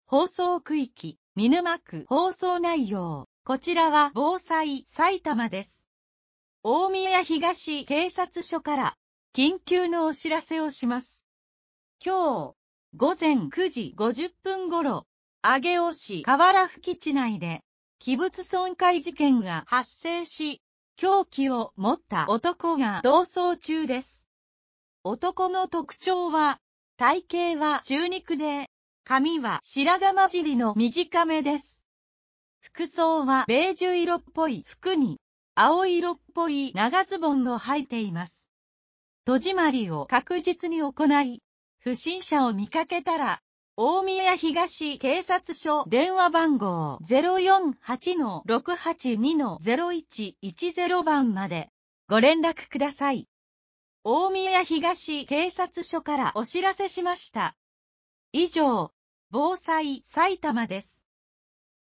警察からの緊急放送